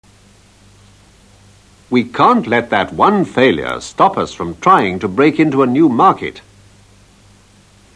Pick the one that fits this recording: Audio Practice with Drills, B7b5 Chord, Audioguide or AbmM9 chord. Audio Practice with Drills